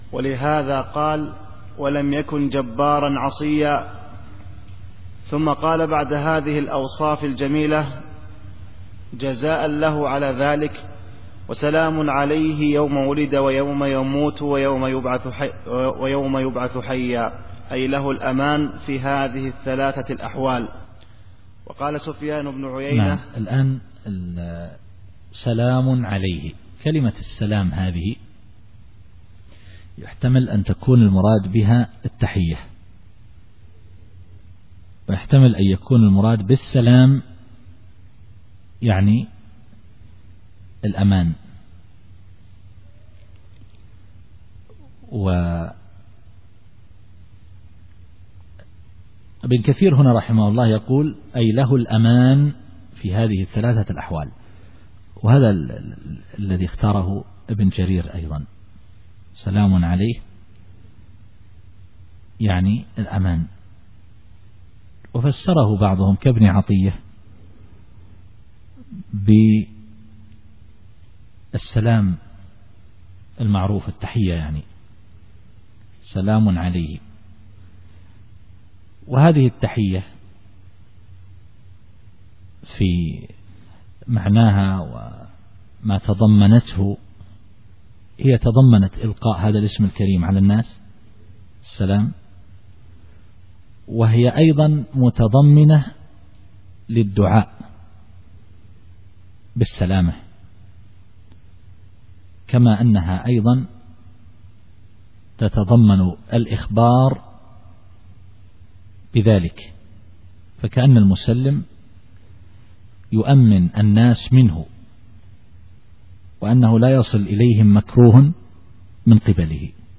التفسير الصوتي [مريم / 15]